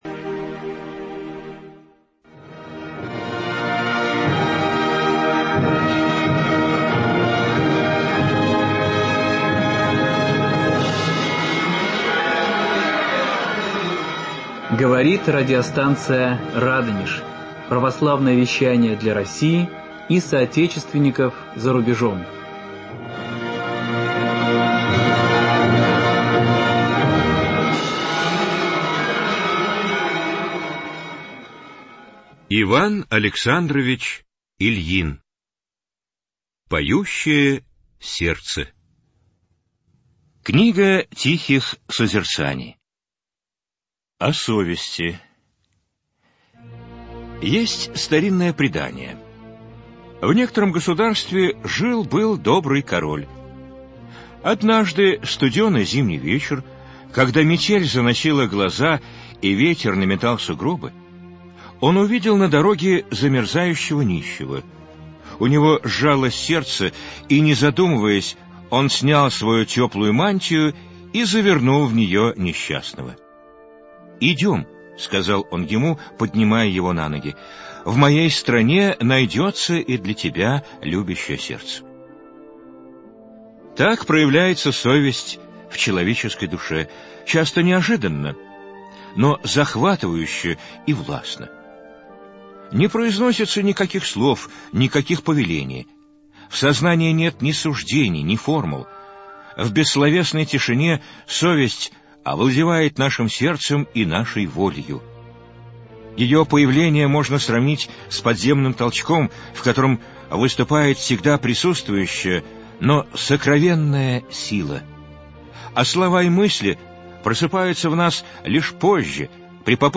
Пасхальное слово приснопамятного протопресвитера Александра Шмемана